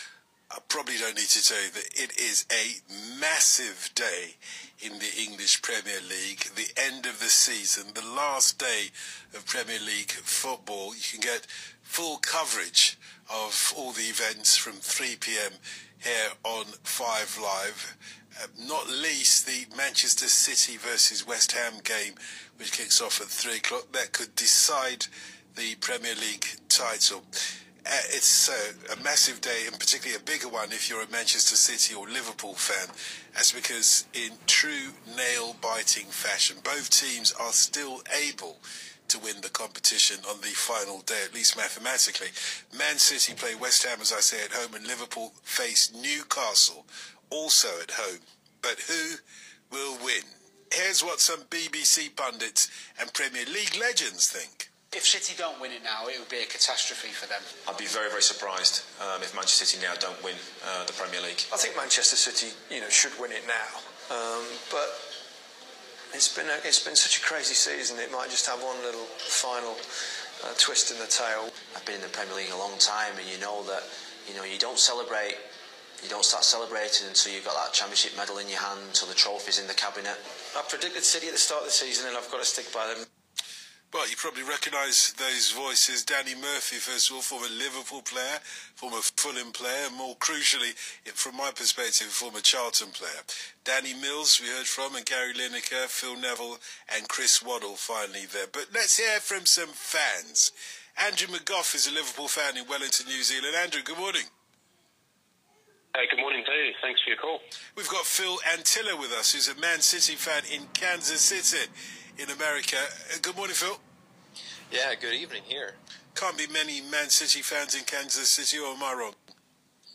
BBC-Radio-5-Live-LFC-Interview.m4a